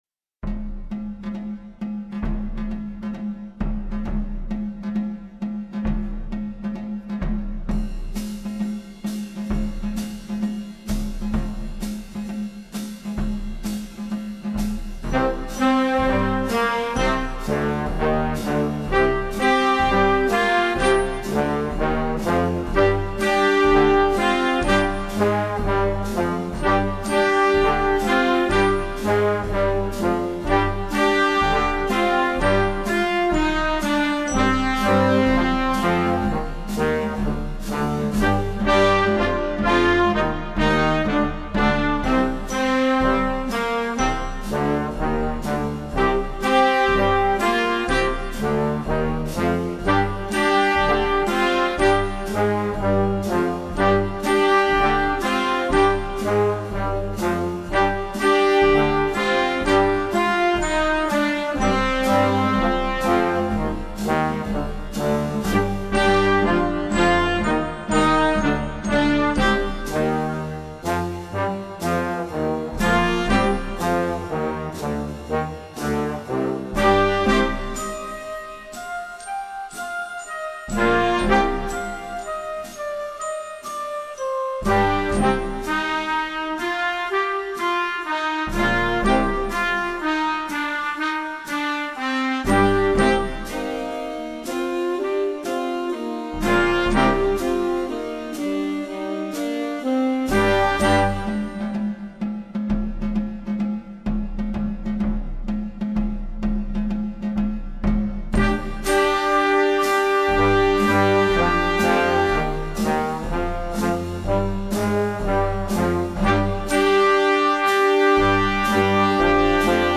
Rehearsal Audio Tracks
Each track will start with a few seconds of silence followed buy a click count off.
Beginner Band: